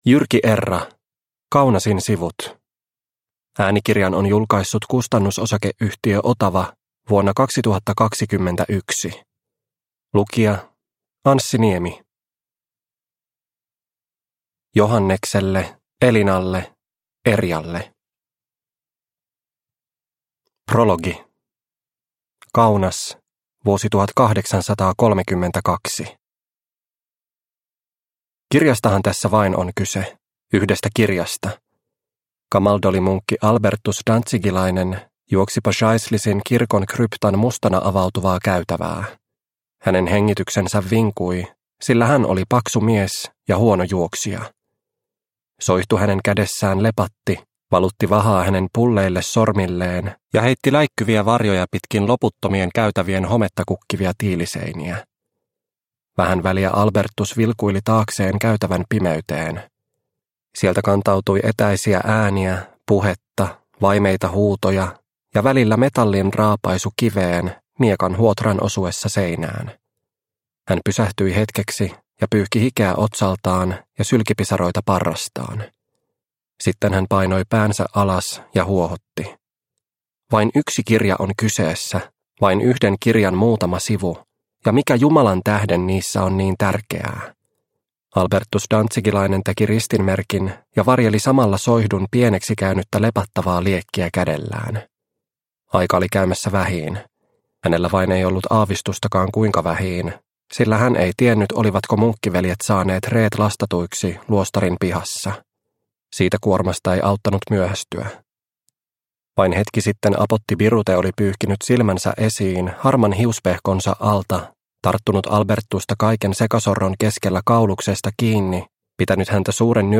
Kaunasin sivut – Ljudbok – Laddas ner